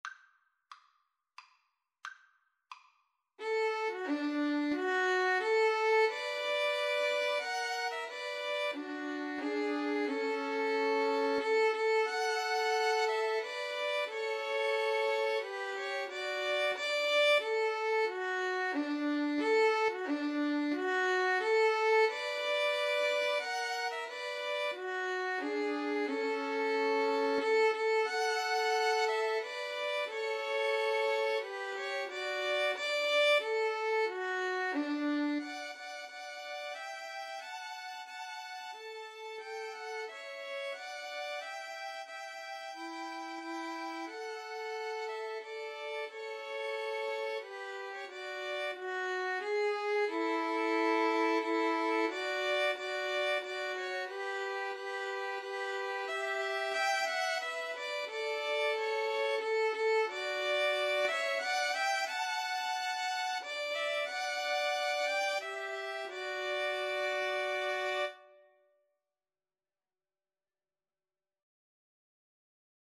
D major (Sounding Pitch) (View more D major Music for Violin Trio )
Maestoso = c.90
Traditional (View more Traditional Violin Trio Music)
star_spangled_banner_3VLN_kar3.mp3